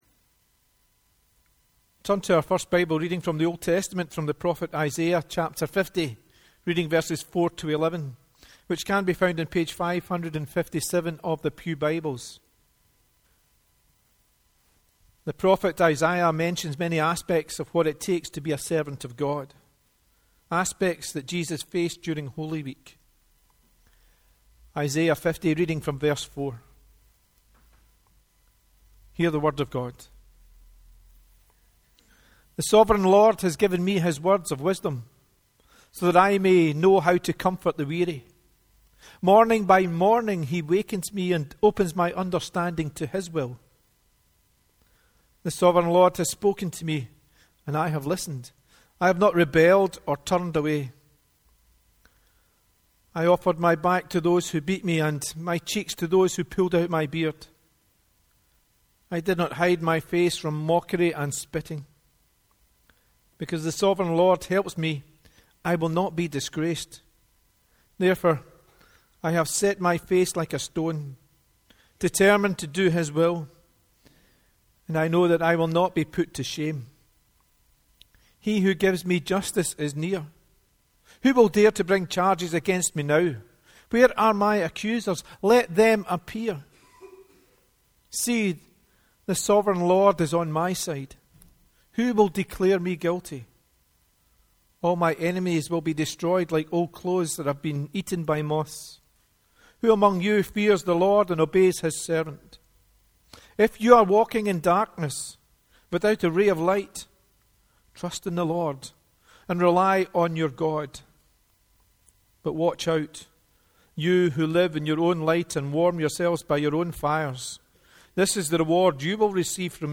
The Scripture Readings prior to the Sermon are Isaiah 50: 4-11 and Mark 11: 1-11